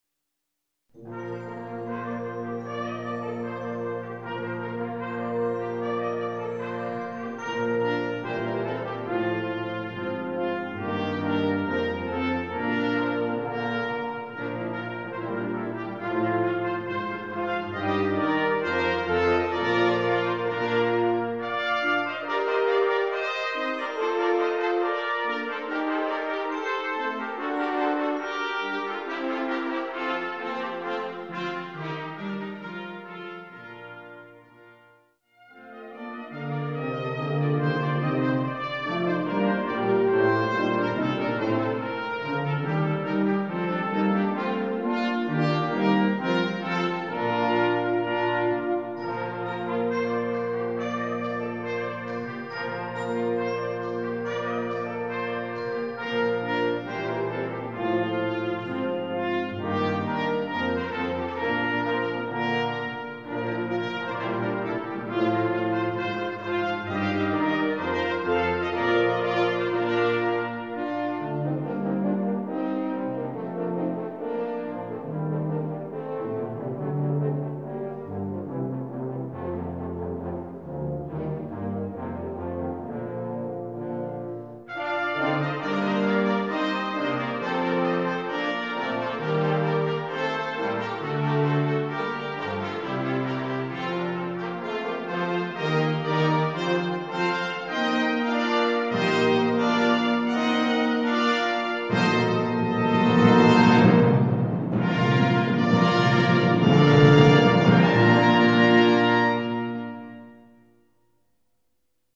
Voicing: Brass Sextet